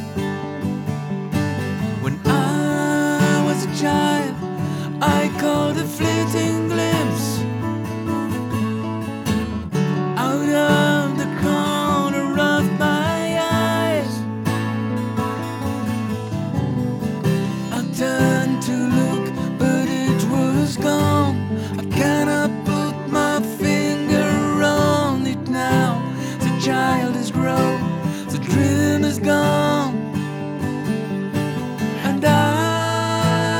voila un export cubase normal sans le magneto donc